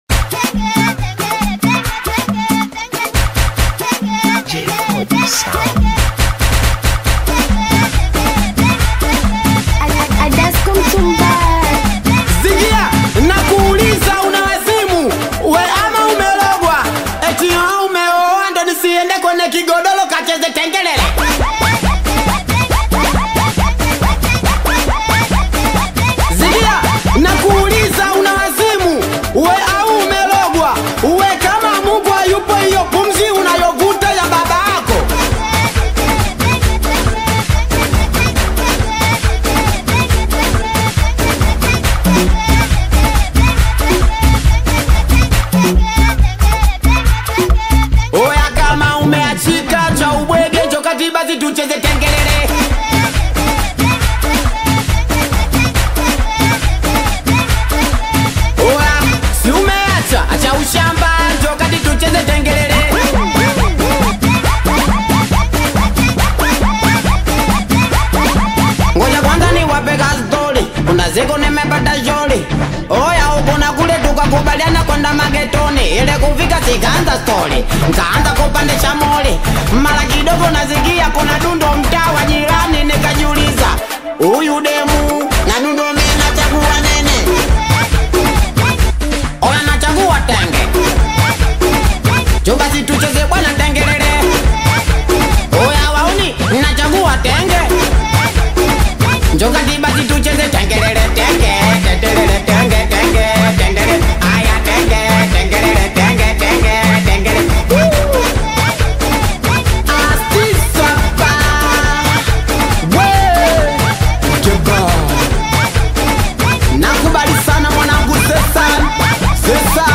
Singeli music track
Tanzanian Bongo Flava singeli
Singeli song